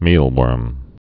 (mēlwûrm)